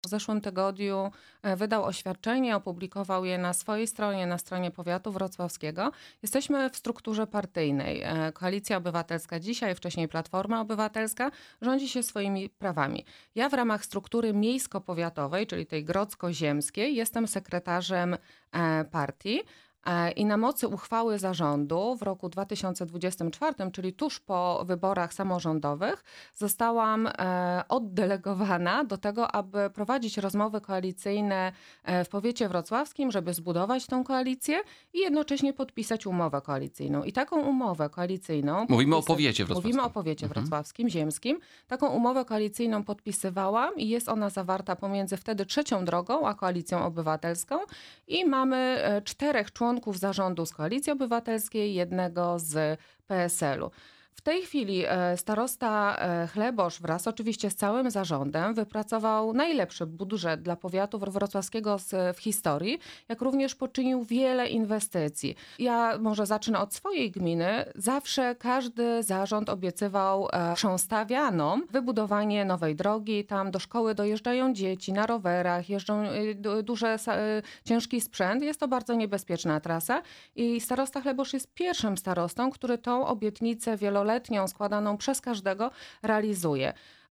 Naszym „Porannym Gościem” była posłanka na Sejm Koalicji Obywatelskiej Anna Sobolak.